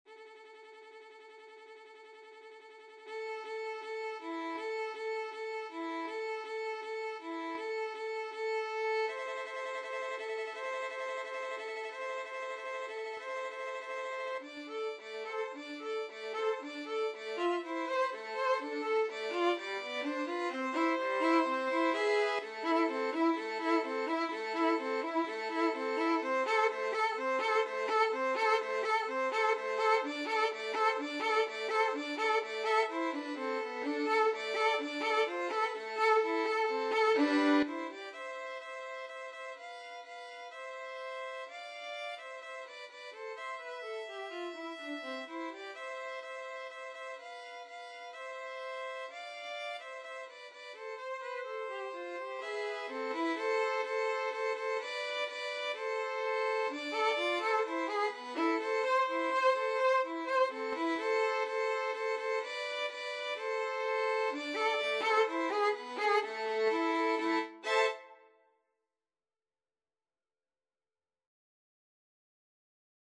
Free Sheet music for Violin Duet
Violin 1Violin 2
A minor (Sounding Pitch) (View more A minor Music for Violin Duet )
2/2 (View more 2/2 Music)
Andante =c.80
Traditional (View more Traditional Violin Duet Music)